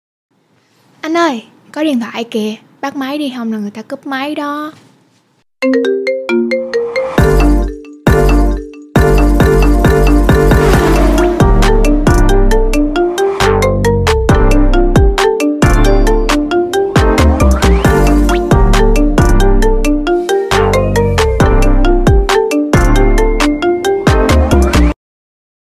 Thể loại: Nhạc chuông
Description: Nhạc chuông Anh ơi có điện thoại kìa, Bắt máy đi... Không người ta cúp máy đó TikTok là nhạc chuông giọng con gái nói dễ thương.